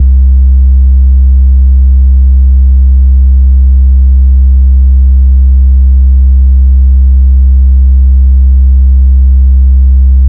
808s
DDWV BASS 2.wav